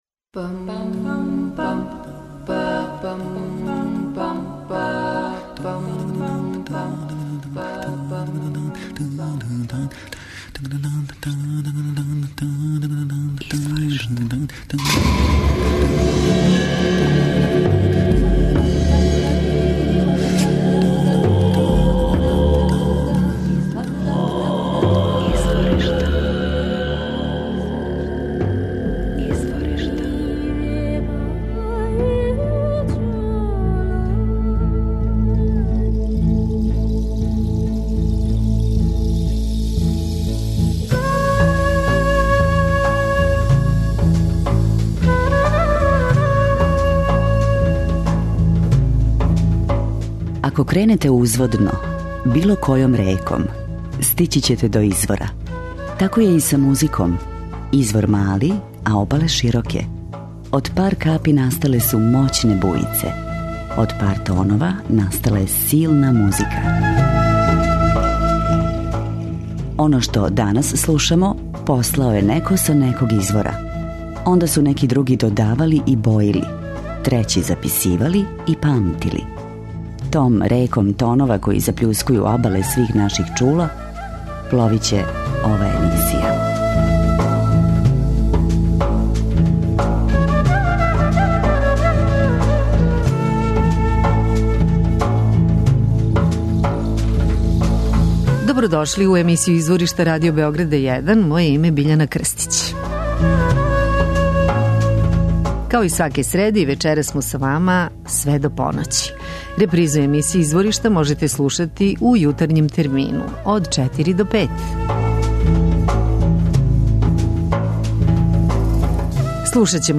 Албум су снимали у Паризу, Лондону и Лос Анђелесу.